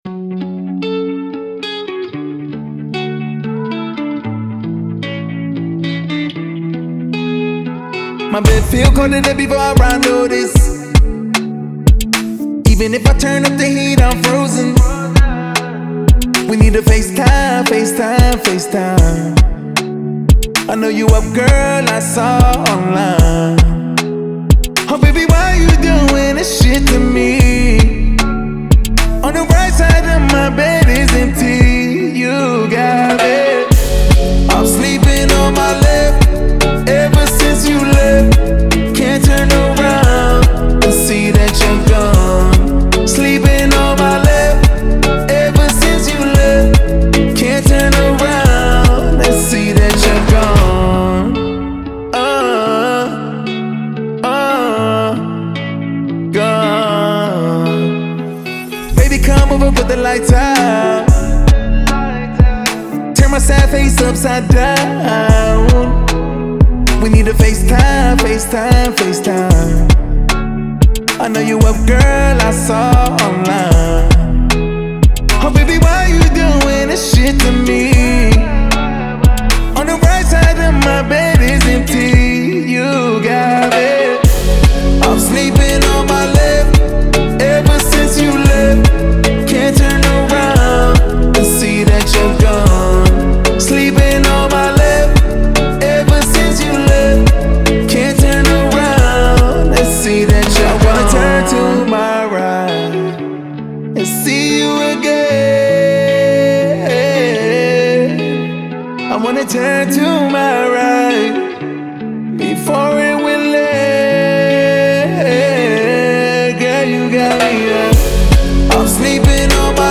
это трек в жанре хип-хоп с элементами R&B
мелодичный вокал с ритмичным битом